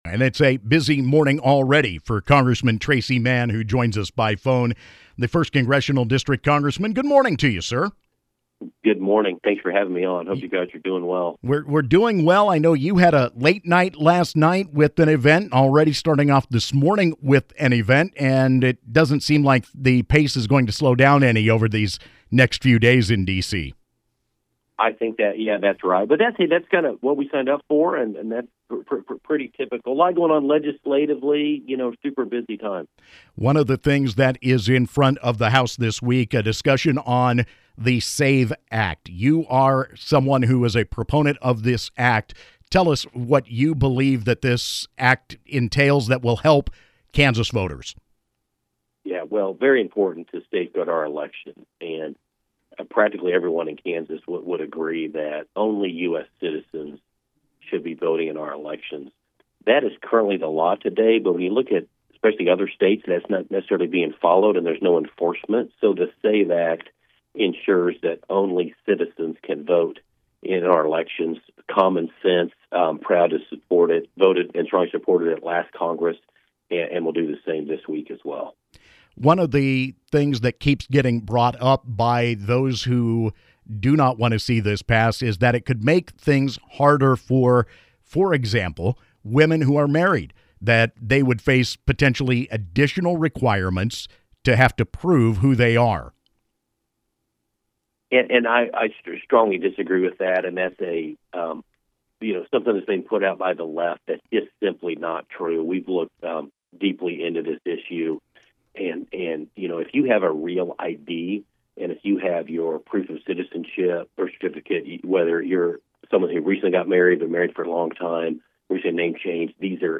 Kansas 1st District Congressman Tracey Mann called into KMAN’s Morning News Wednesday from Washington, D.C. to discuss activity in Congress including a potential vote on the Safeguard American Voter Eligibility (SAVE) Act and market reaction to President Trump’s newly imposed tariffs on other countries.